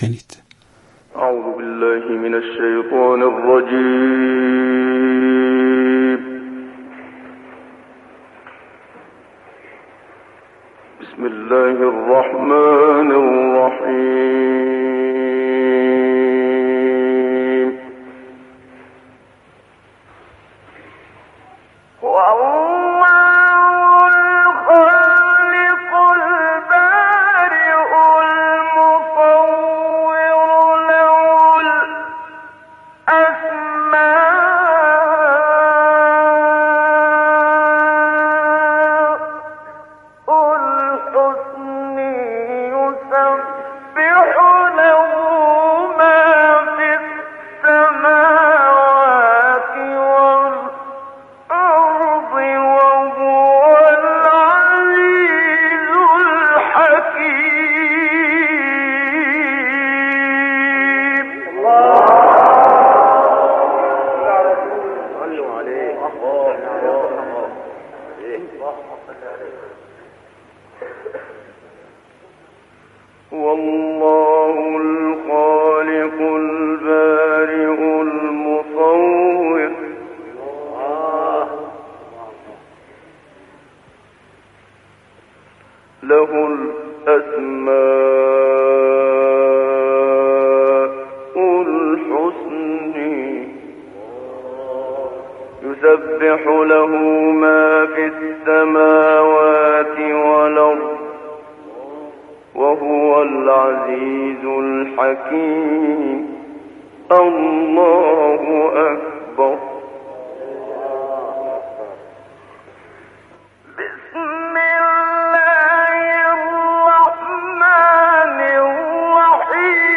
تلاوت محمدصدیق منشاوی از آیه آخر سوره حشر و سوره طارق
در این تلاوت مرحوم منشاوی که مربوط به بخش‌هایی از سوره‌های مبارکه حشر، طارق و فجر است، ورودش به مقام نهاوند احساس می‌شود که این ورود بسیار زیبا و منحصر به‌فرد و در واقع خاص خاندان منشاوی است که همگی در این زمینه تبحر داشته‌اند و اوج پختگی و اجرای این ردیف‌ها نیز متعلق به محمدصدیق منشاوی است.
هنگامی که وی به این مقام وارد شد و آن را در آیات پایانی سوره مبارکه حشر اجرا کرد، به سوره مبارکه طارق وارد شد که بار فرم و جذبه بسیار فوق‌العاده و ترکیبی دارد که در نهایت به مقام سه‌گاه منتهی می‌شود و در نوع خود بسیار زیبا و دل‌انگیز و جزء تلاوت‌ها و ترکیب‌های بسیار گوش‌نواز است.
محمدصدیق منشاوی در اوج به رست بازگشت دارد و به مقام چهارگاه اشاره می‌کند که می‌توان گفت با حزنی شگفت‌انگیز همراه است.